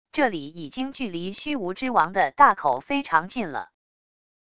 这里已经距离虚无之王的大口非常近了wav音频生成系统WAV Audio Player